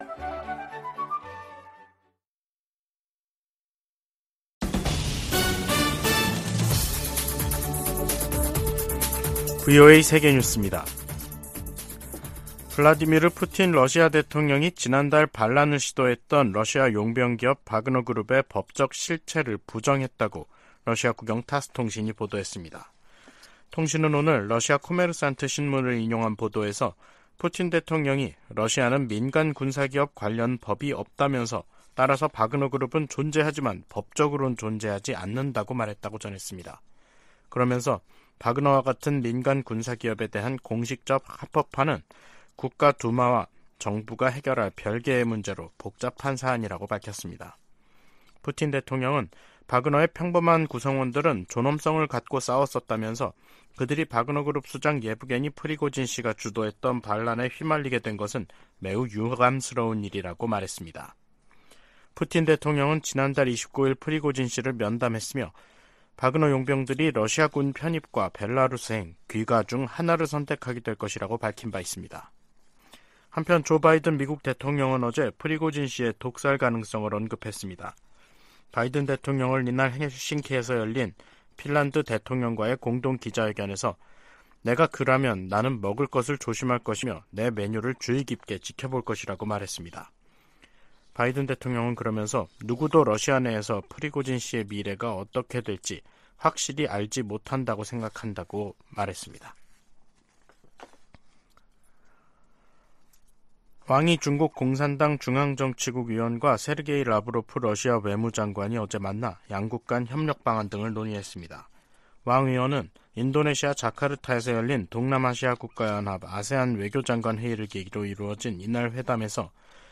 VOA 한국어 간판 뉴스 프로그램 '뉴스 투데이', 2023년 7월 14일 2부 방송입니다. 미한일과 유럽 나라들이 북한의 대륙간탄도미사일(ICBM) 발사 문제를 논의한 유엔 안보리 회의에서 북한을 강력 규탄하며 안보리의 단합된 대응을 거듭 촉구했습니다. 김정은 북한 국무위원장이 할 수 있는 최선의 방안은 대화 복귀라고 미 백악관이 강조했습니다.